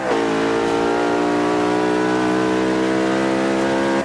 Index of /server/sound/vehicles/lwcars/merc_slk55